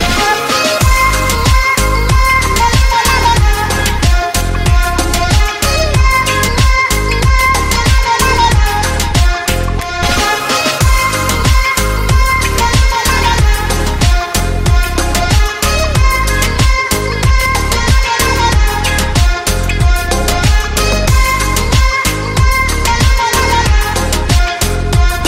громкие
заводные
K-Pop